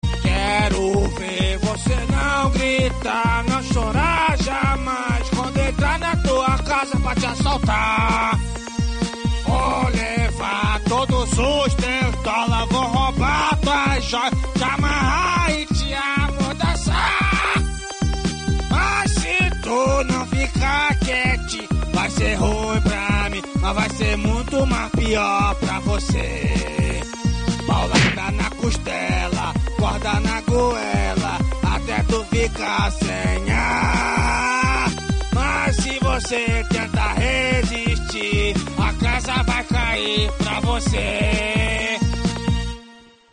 j0_low_quality.mp3